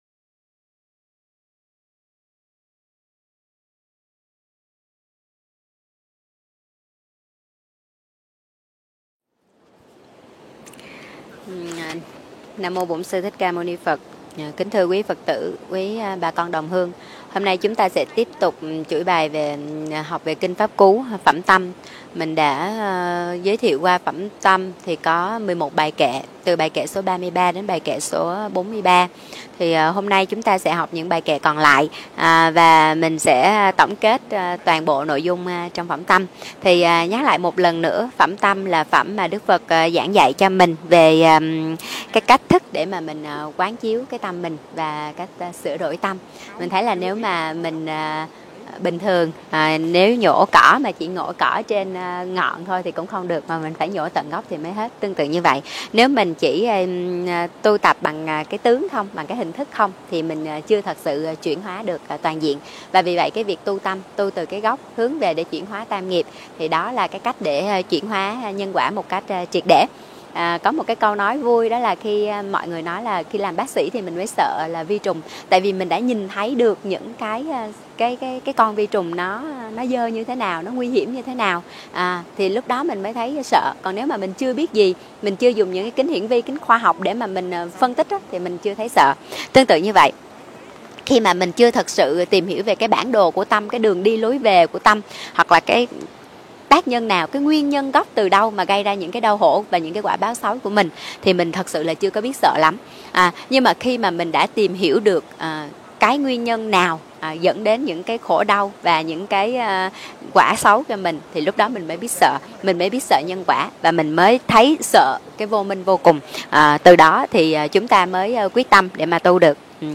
Mời quý vị nghe mp3 thuyết pháp Giữ tâm như thành trì